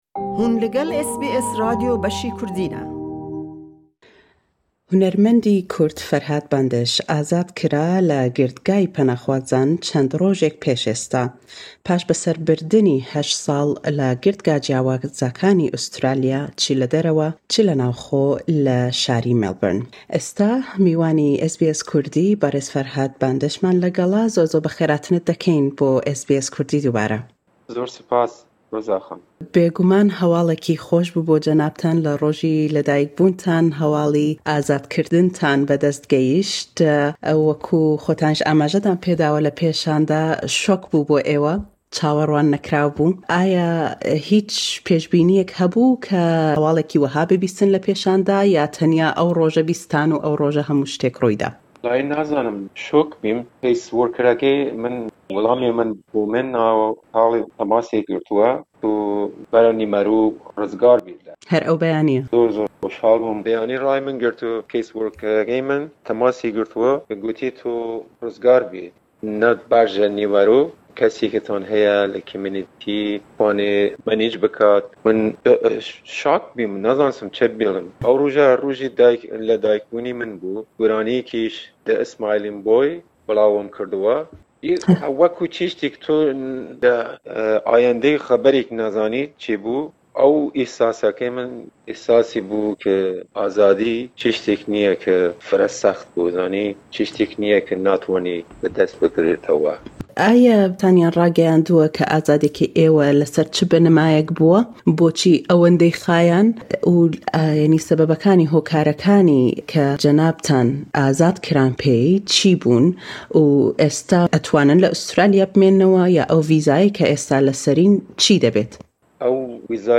Le em lêdwane taybetey SBS Kurdî